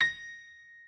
pianoadrib1_33.ogg